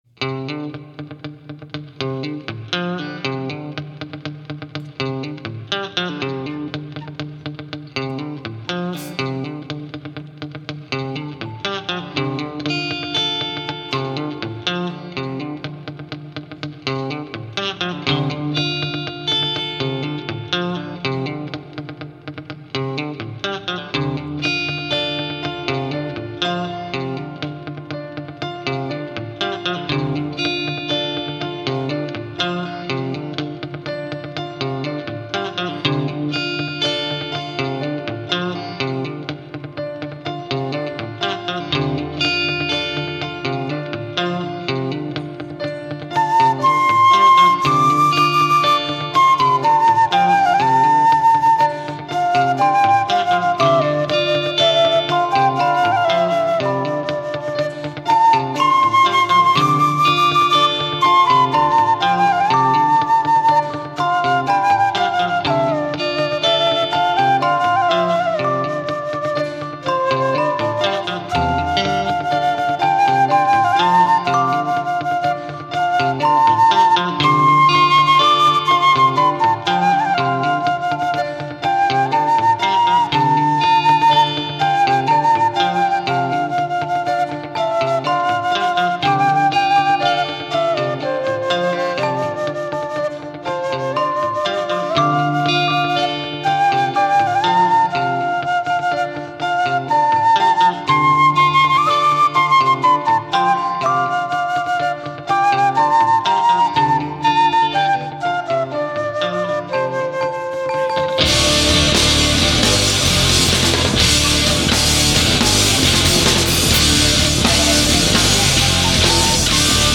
Prog 2 chitarre, basso, tamburi, flauto trav.
con gruppo di allievi diretti